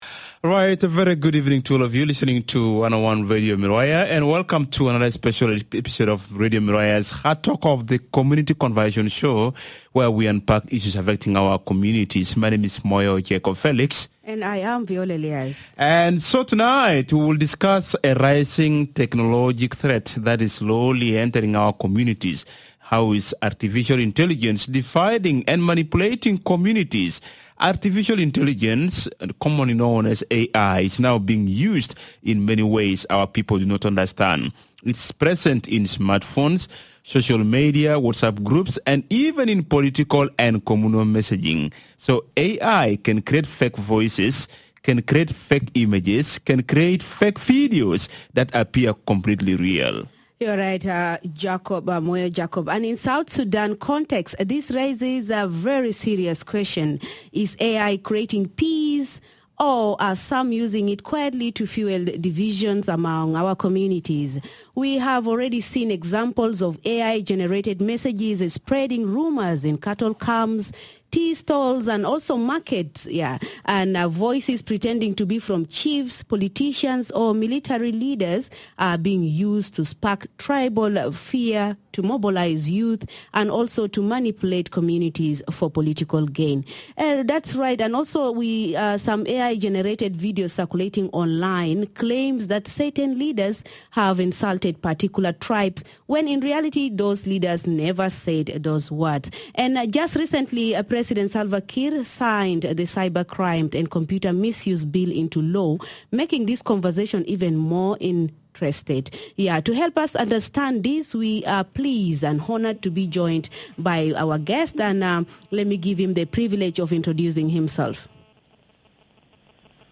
Hard Talk segment